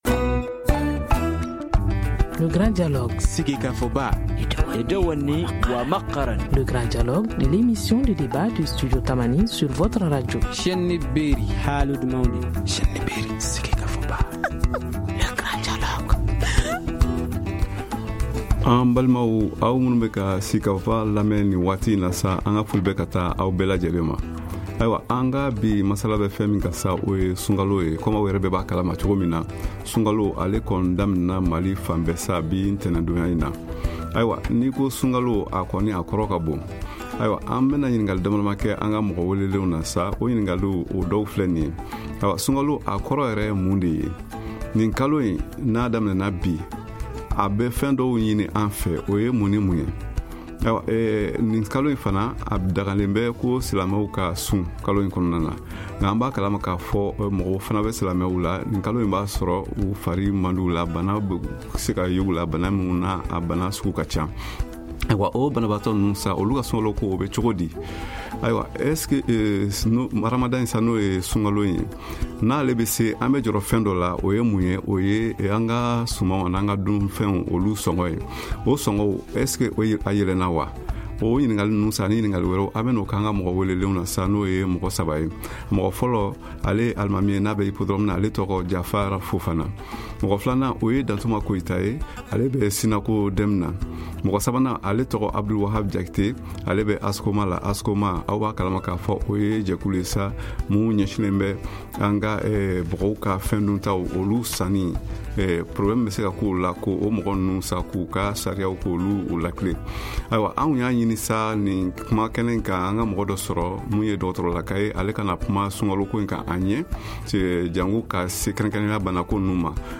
A ces questions et à bien d’autres vont répondre ces invités.